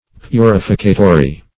Search Result for " purificatory" : The Collaborative International Dictionary of English v.0.48: Purificatory \Pu*rif"i*ca*to*ry\, a. [L. purificatorius.]